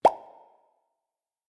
Звуки лопающихся пузырьков
Звук лопающегося пузыря один раз как жвачка